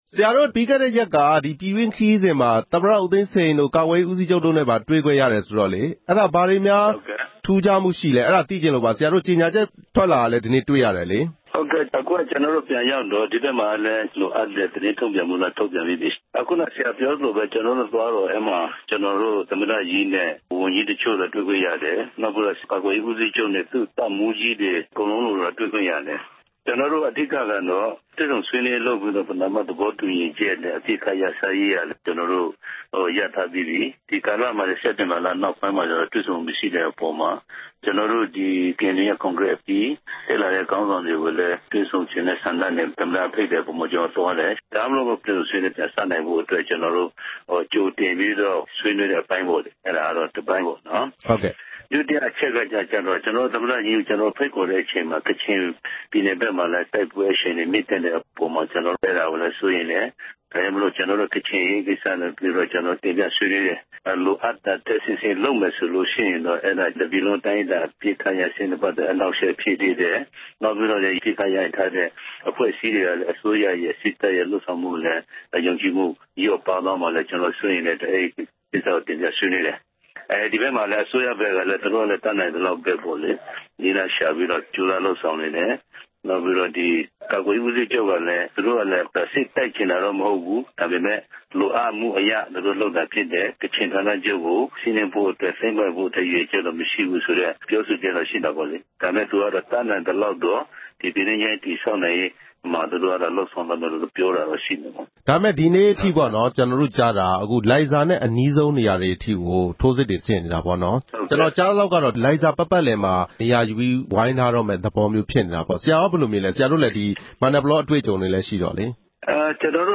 အစိုးရနဲ့ ကေအဲန်ယူတွေ့ဆုံပွဲ မေးမြန်းချက်